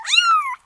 kitten.ogg